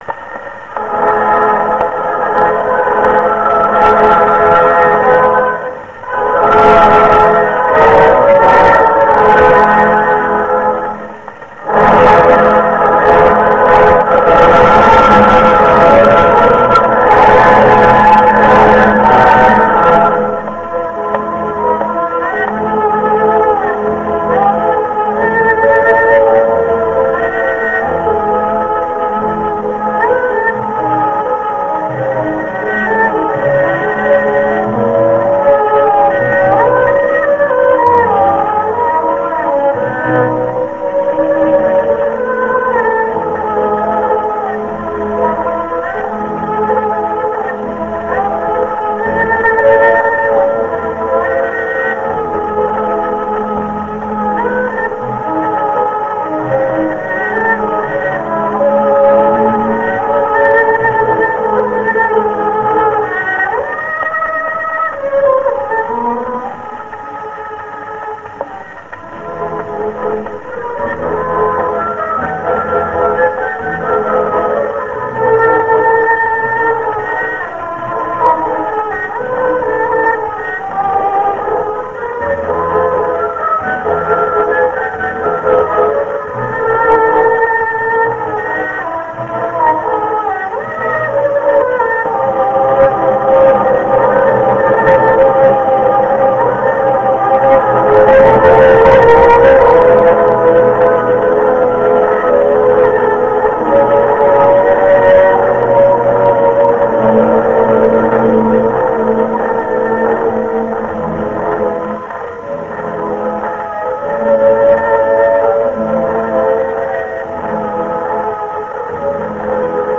蓄音機から流れる音を録音してみました!!
ノスタルジックな雑音混じりの音楽を
オーケストラ
(レコードの状態が悪いため、雑音が多いです)